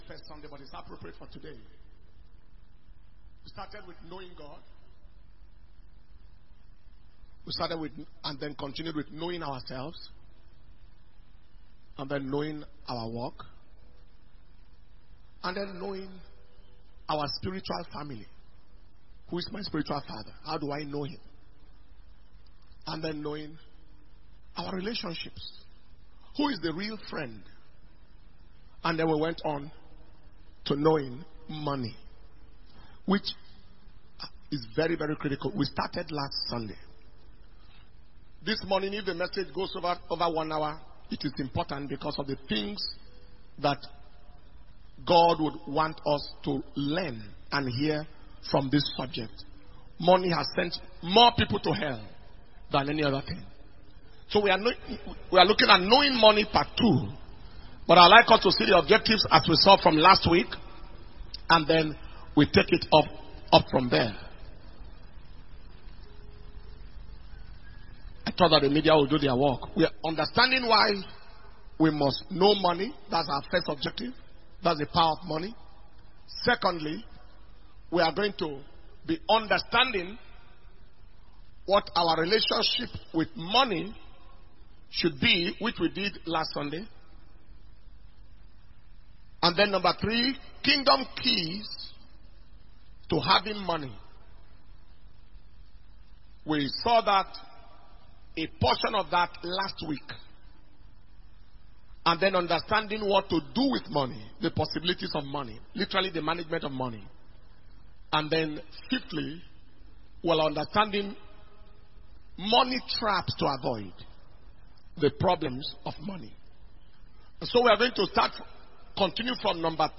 Message
July 2023 Blessing Sunday